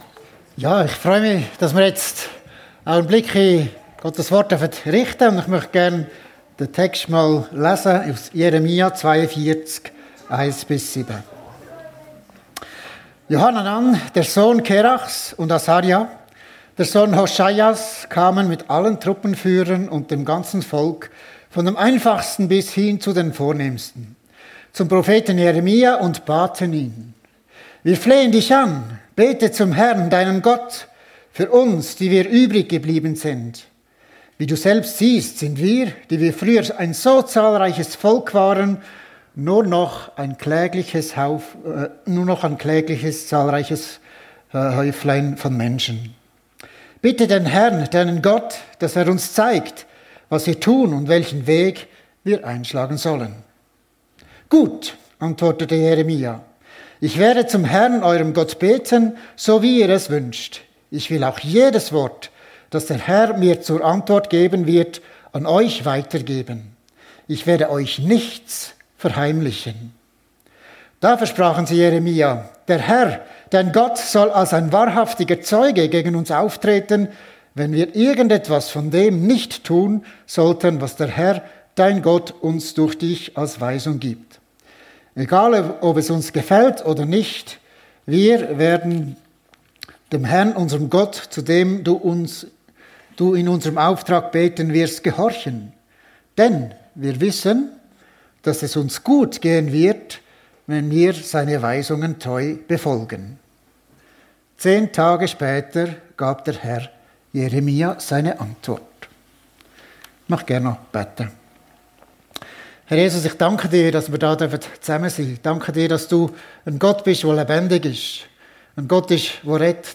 Sich voll und ganz dem ausliefern, was Gott will ~ FEG Sumiswald - Predigten Podcast